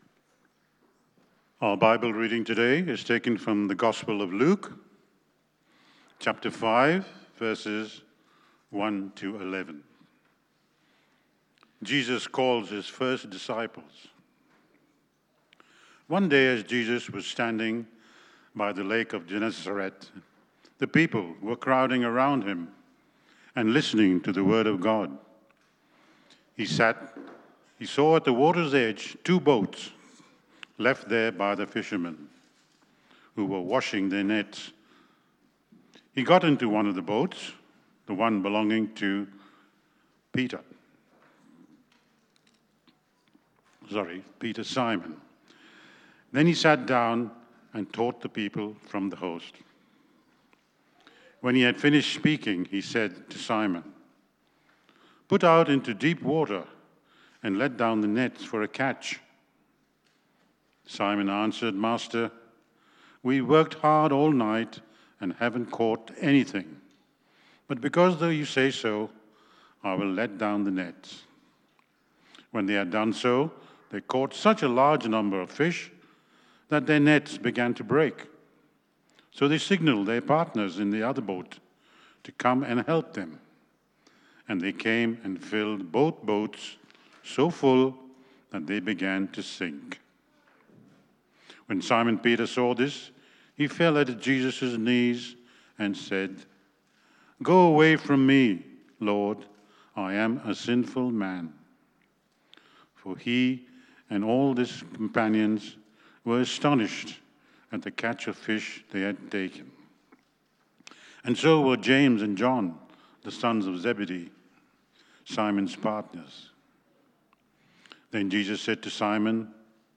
Luke 5:1-11 Service Type: AM  Have you ever asked the question 'Why Me?'